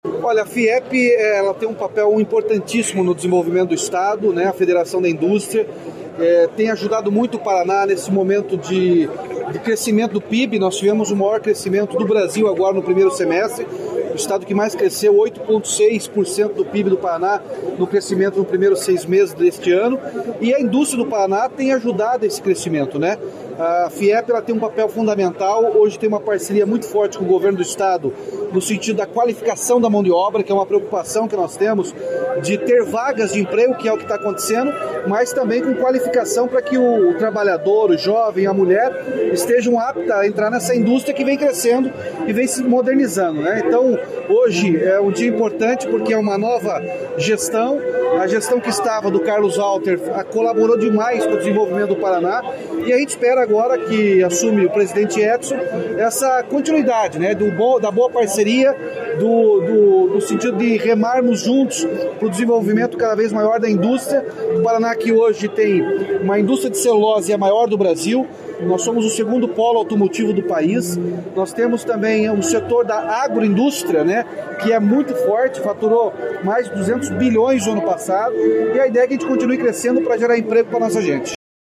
Sonora do governador Ratinho Junior na posse da nova diretoria da Fiep
RATINHO JUNIOR - POSSE FIEP.mp3